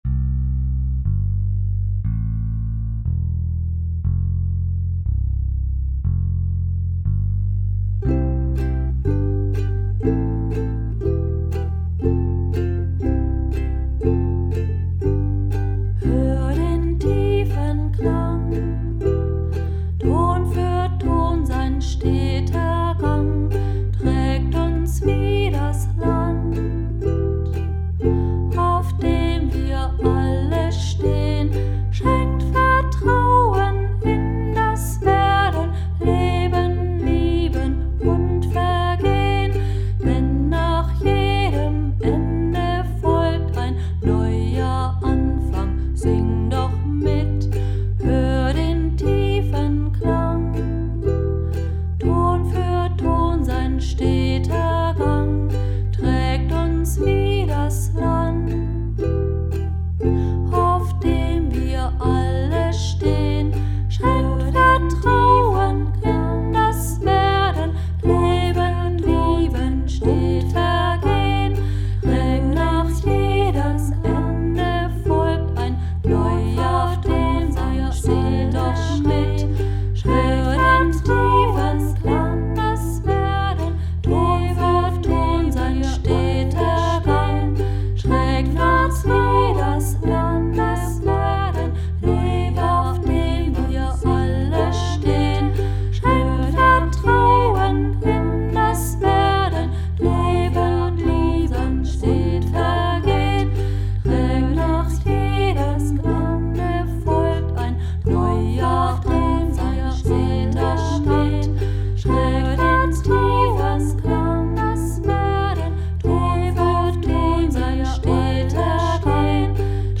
Melodie in Anlehnung an den Kanon in D-Dur von Johann Pachelbel